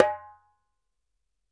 ASHIKO 4 0RR.wav